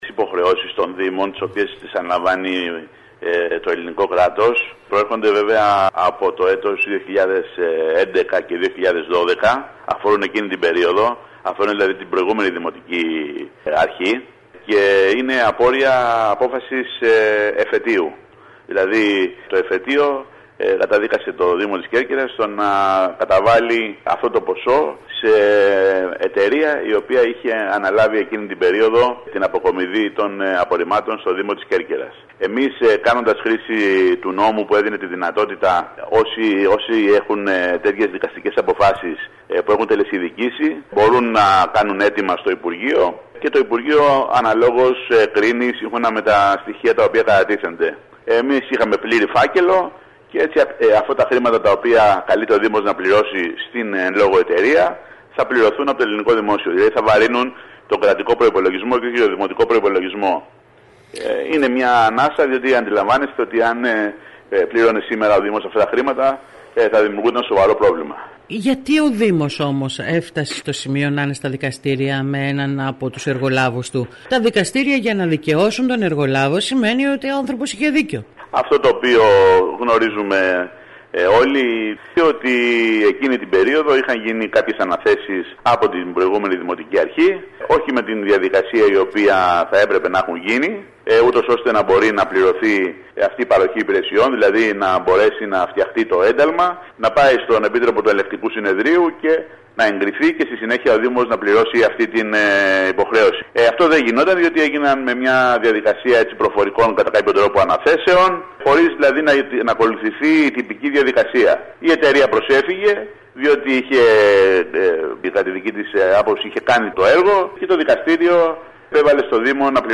Στη συνέντευξη πού έδωσε σήμερα στην ΕΡΤ Κέρκυρας ο αντιδήμαρχος αναφέρει ότι τα χρέη που προέρχονται από τις αναπτυξιακές δεν προβλέπεται να καλύπτονται από τον κρατικό προϋπολογισμό και θα επιβαρύνουν υποχρεωτικά τον προϋπολογισμό του Δήμου.